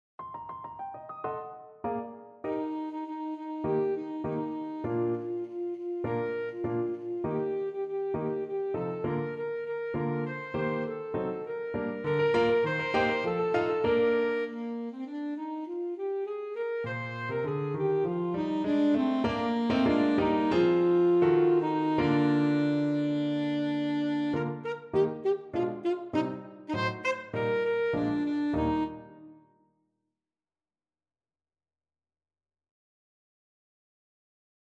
Alto Saxophone version
Alto Saxophone
2/4 (View more 2/4 Music)
Quick and Light
Classical (View more Classical Saxophone Music)